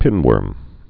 (pĭnwûrm)